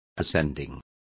Complete with pronunciation of the translation of ascending.